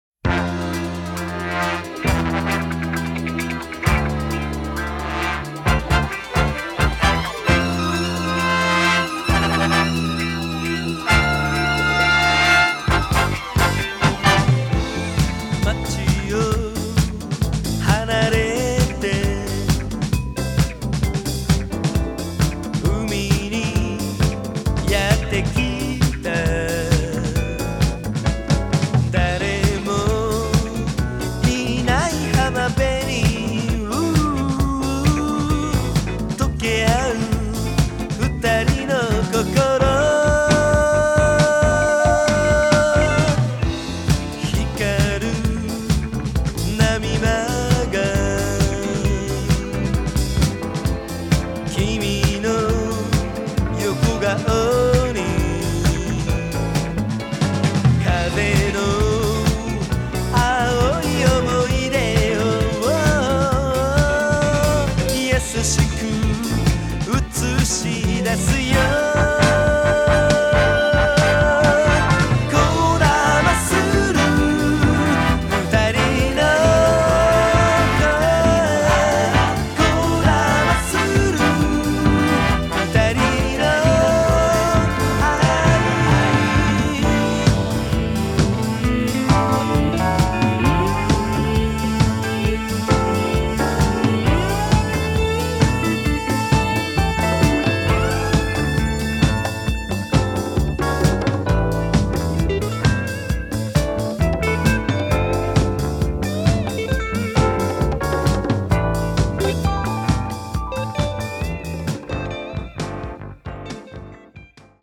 ジャンル(スタイル) JAPANESE POP / CITY POP